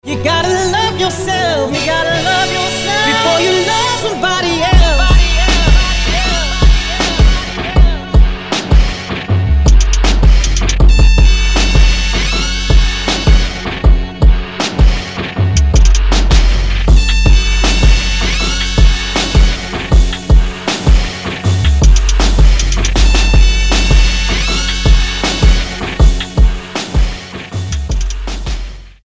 • Качество: 320, Stereo
Хип-хоп
RnB
Rap